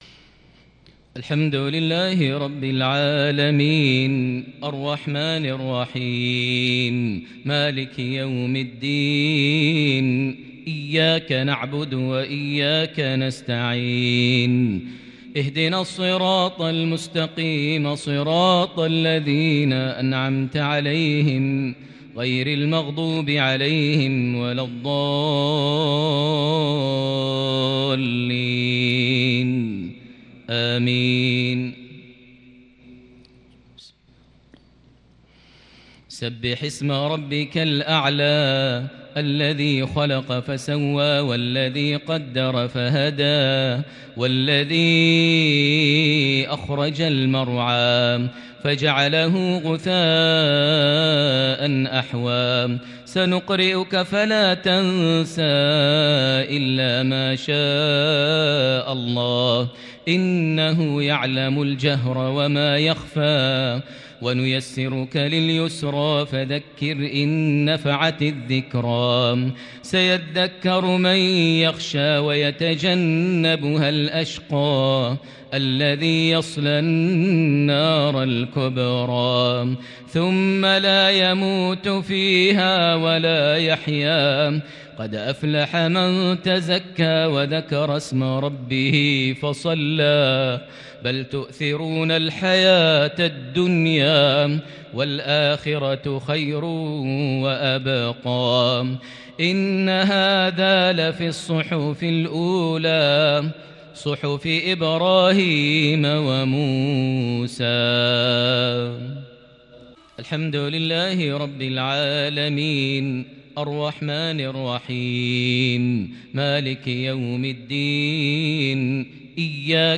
تلاوة منفردة لسورتي الأعلى - الغاشية صلاة الجمعة |23 جمادى الآخر 1442ه > 1442 هـ > الفروض - تلاوات ماهر المعيقلي